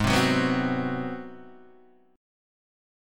G#7#9 chord {4 3 4 4 1 2} chord